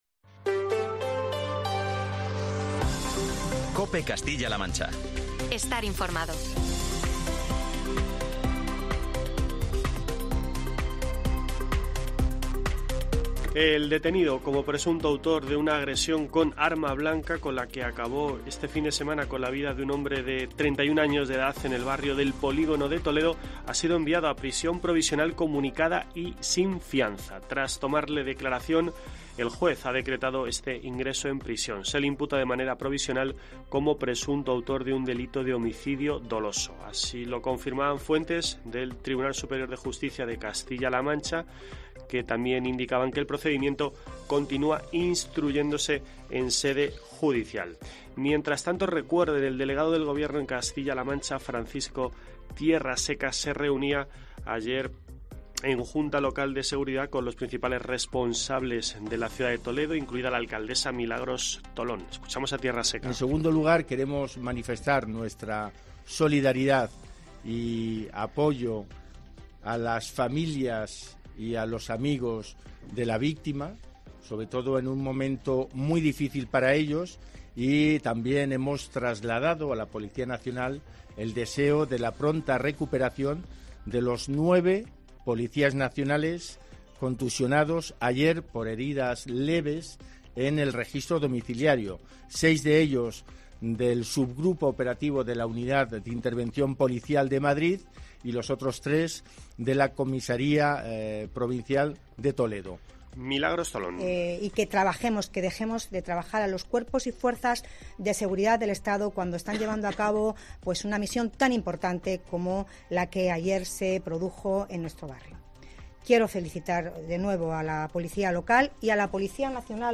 En declaraciones a los medios, tras la reunión de la Junta de Seguridad que ella misma solicitó este domingo al hilo de los hechos violentos desencadenados tras la detención del presunto autor de la muerte de un varón de 31 años, Tolón ha agradecido al delegado del Gobierno, Francisco Tierraseca, que haya tenido a bien celebrar este reunión, con el objetivo de que este barrio, que aglutina a más de 26.000 toledanos, contará con una información veraz y "fluida" tras lo ocurrido.